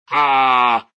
SOldmanAlarm.ogg